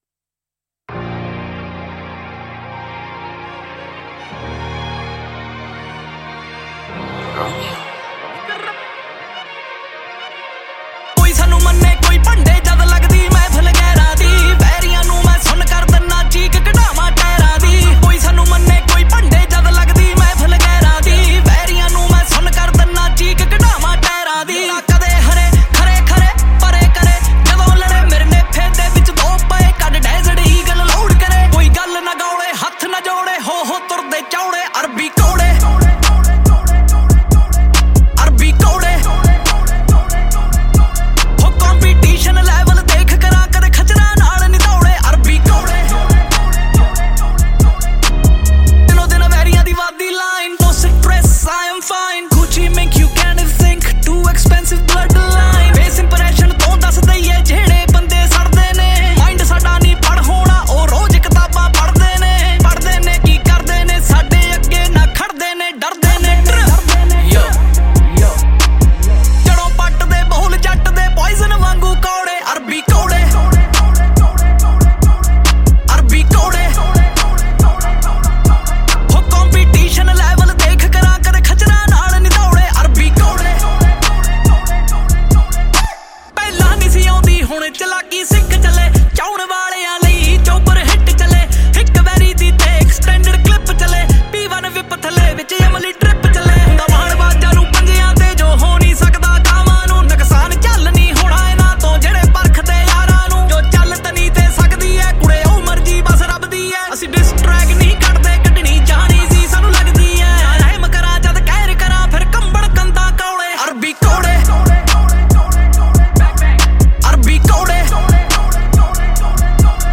2. Punjabi Single Track